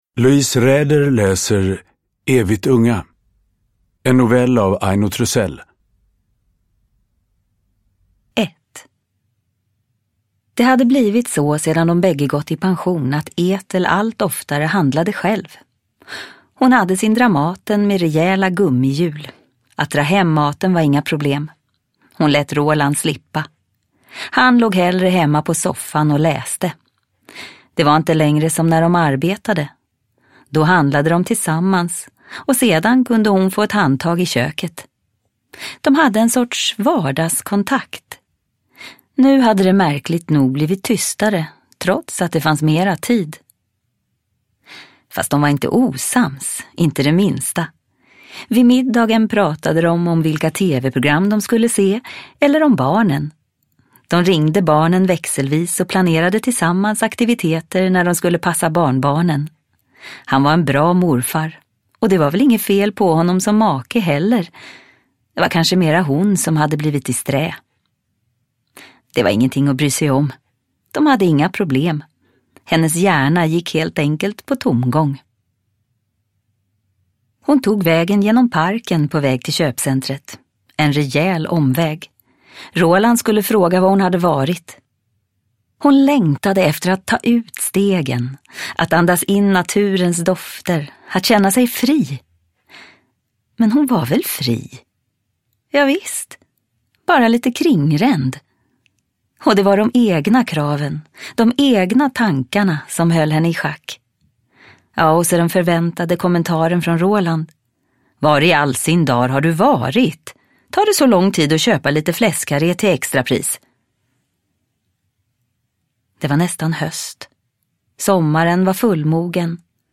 Evigt unga – Ljudbok – Laddas ner
En StorySide novell på 48 minuter.